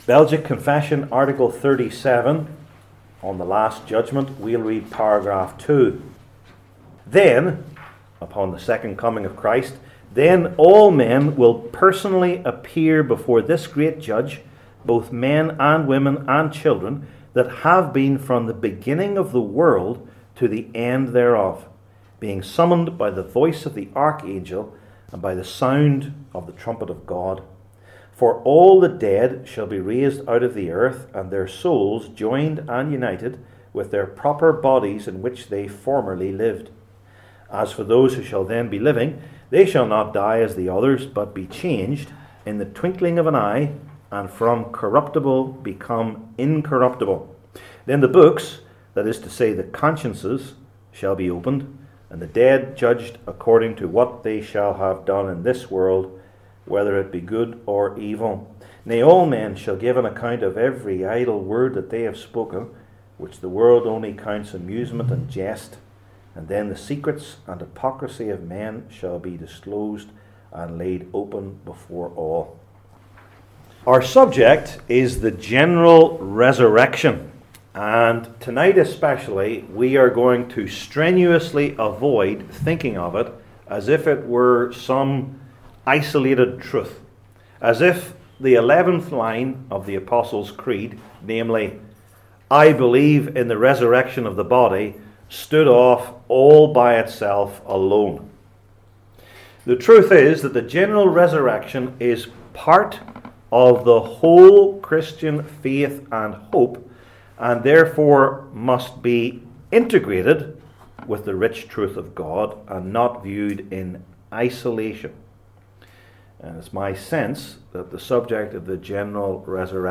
II Corinthians 4 Service Type: Belgic Confession Classes THE LAST JUDGMENT …